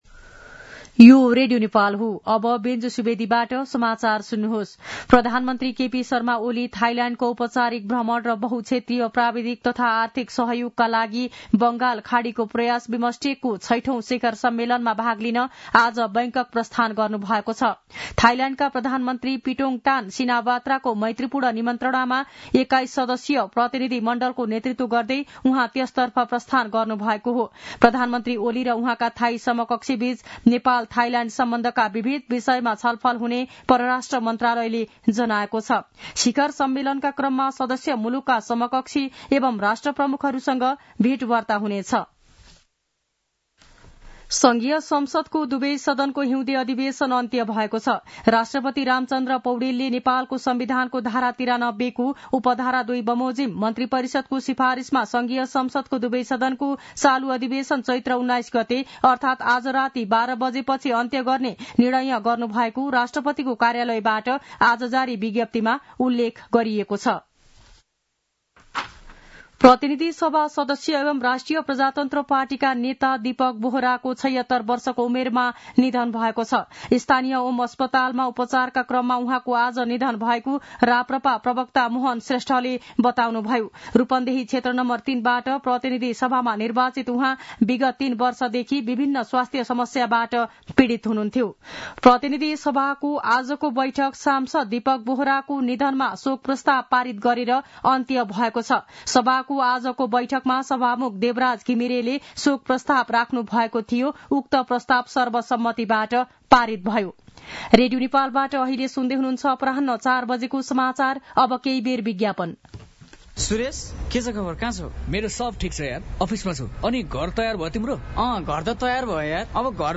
दिउँसो ४ बजेको नेपाली समाचार : १९ चैत , २०८१
4-pm-news-.mp3